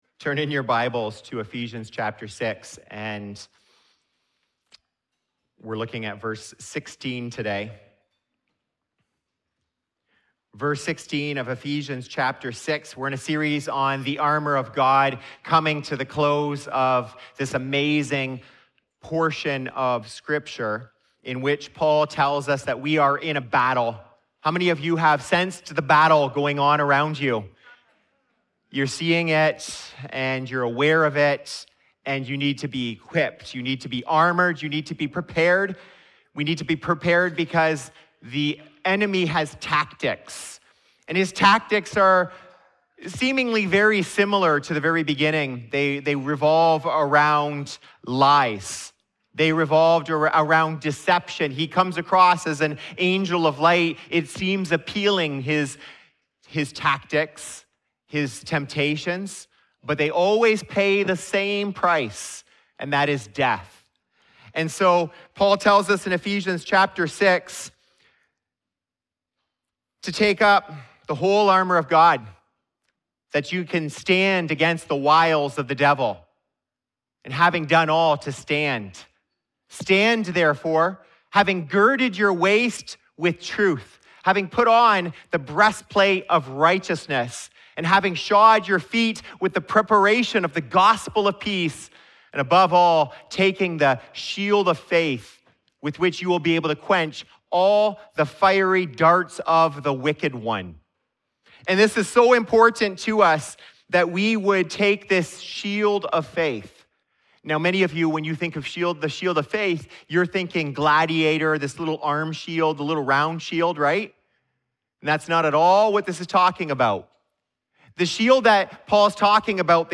In this sermon, we see how worship fireproofs our shield, the Word enlarges it, and trust strengthens it.